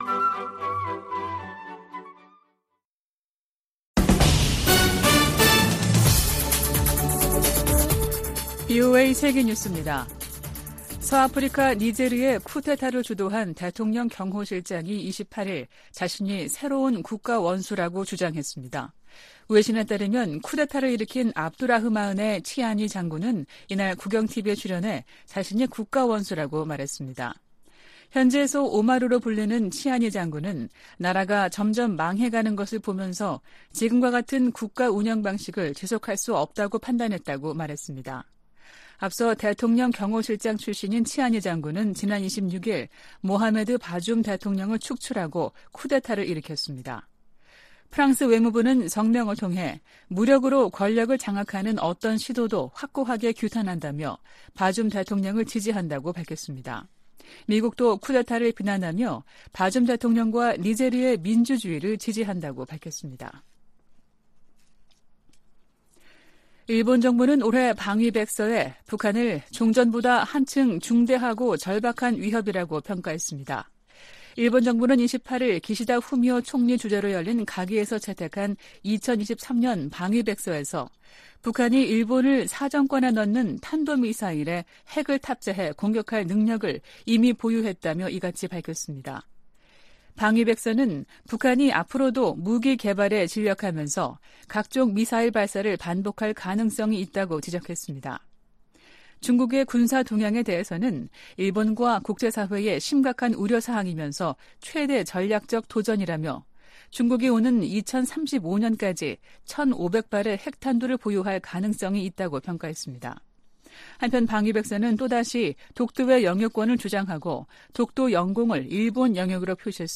VOA 한국어 아침 뉴스 프로그램 '워싱턴 뉴스 광장' 2023년 7월 29일 방송입니다. 미 국무부는 러시아가 북한의 불법 무기 프로그램을 지원하고 있다고 비난했습니다. 로이드 오스틴 미 국방장관은 미한 상호방위조약이 체결된 지 70년이 지난 지금 동맹은 어느 때보다 강력하다고 밝혔습니다. 북한은 김정은 국무위원장이 참석한 가운데 '전승절' 열병식을 열고 대륙간탄도미사일 등 핵 무력을 과시했습니다.